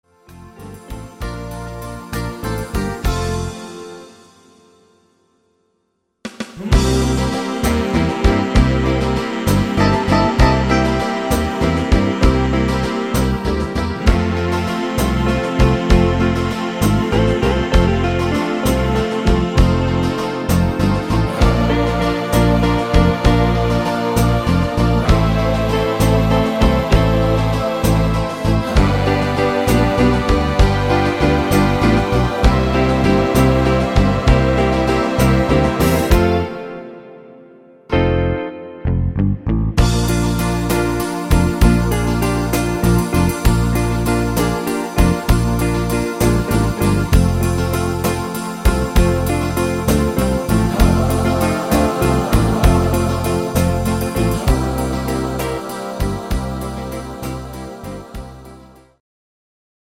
Evergreen der 60er